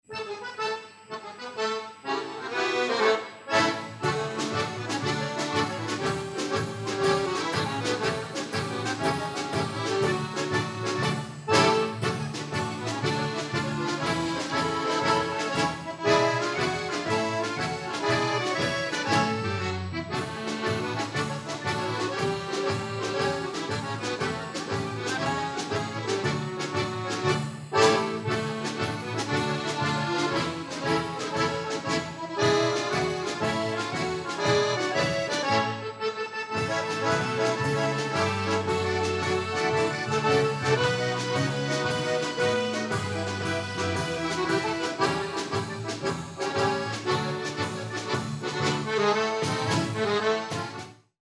Konzert 2006 -Download-Bereich
------Akkordeon-Gruppe------